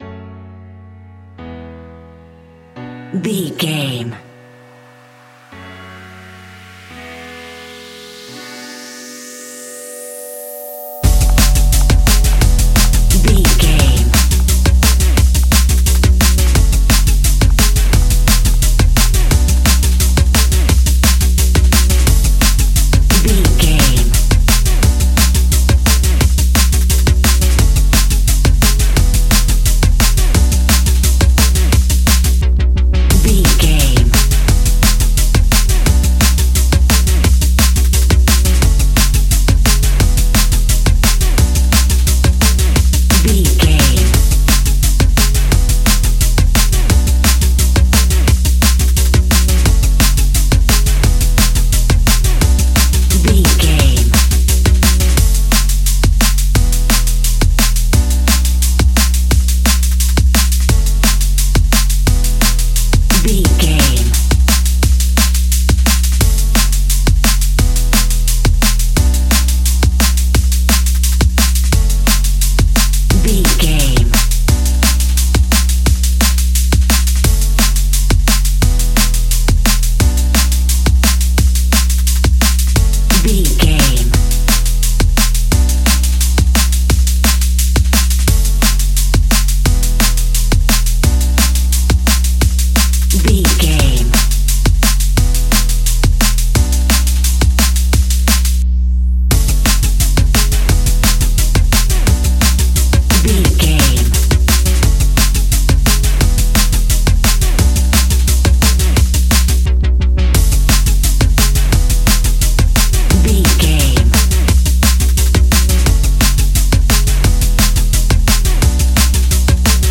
Ionian/Major
electronic
dance
techno
trance
synths
synthwave
instrumentals